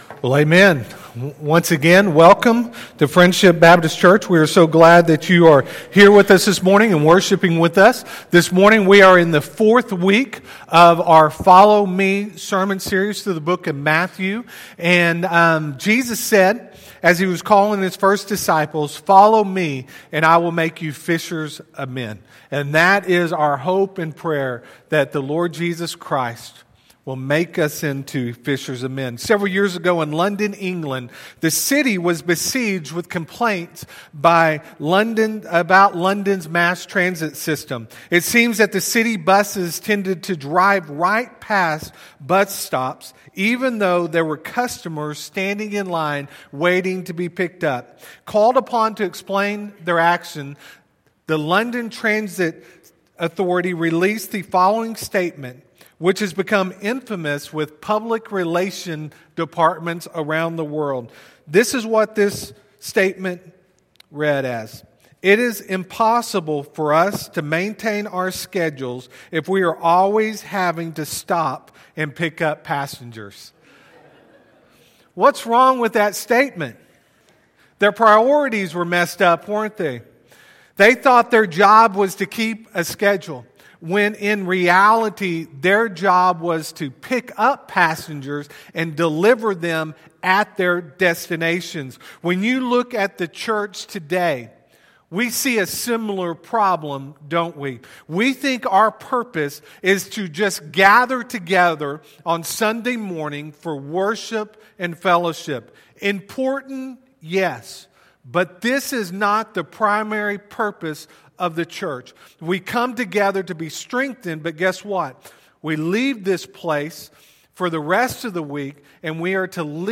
Friendship Baptist Church SERMONS